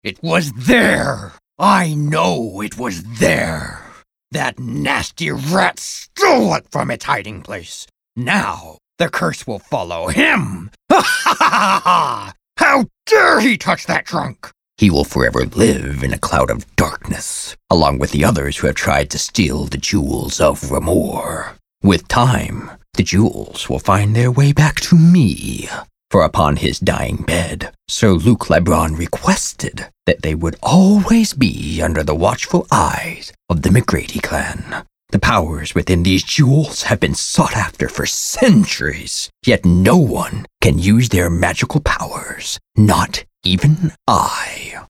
Samples that reveal my full range: intimate storytelling, rich narration, and bold character voices.
Audiobook Demo
Audiobook_Fantasy.mp3